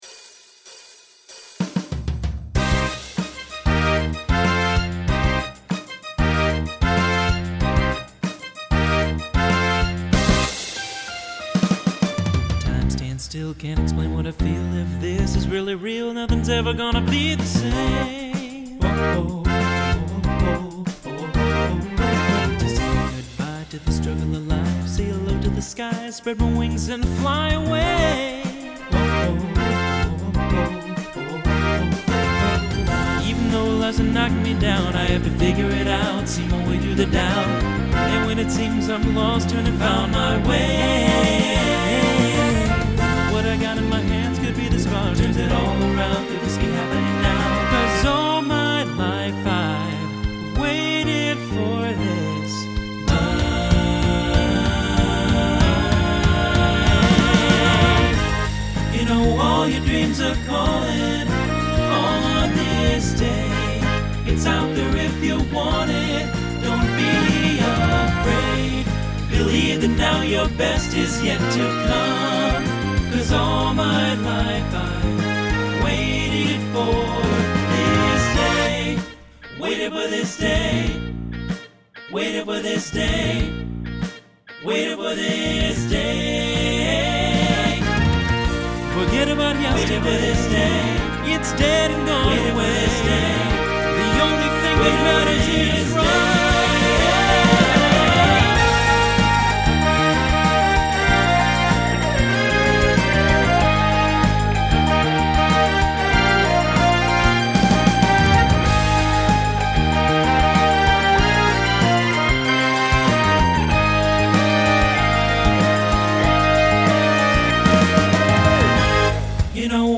Genre Broadway/Film
Transition Voicing TTB